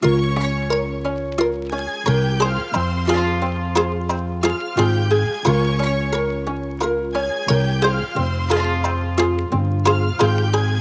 MELODY LOOPS
Nifty (177 BPM – Fm)
UNISON_MELODYLOOP_Nifty-177-BPM-Fm.mp3